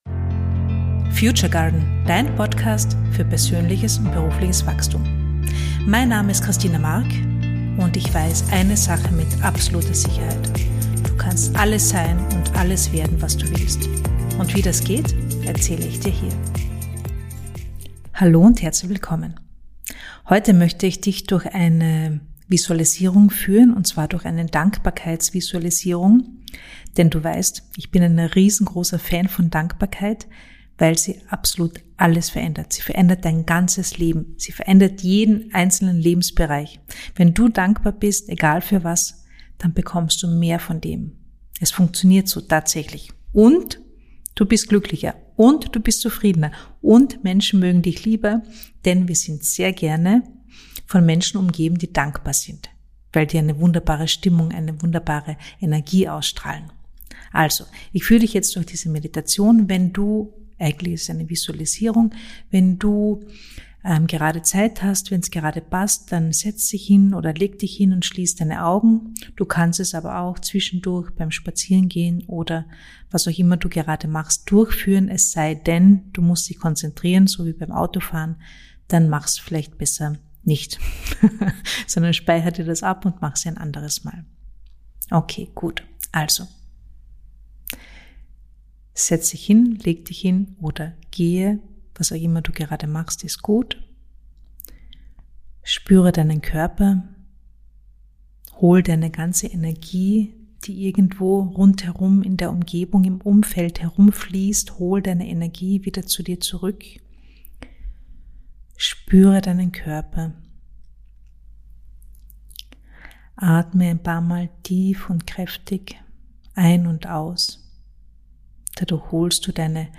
Diese geführte Dankbarkeitsreise hilft dir dabei, Dankbarkeit zu spüren und im Körper zu verankern. Denn: Dankbarkeit verändert einfach ALLES.